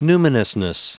Prononciation du mot numinousness en anglais (fichier audio)
Prononciation du mot : numinousness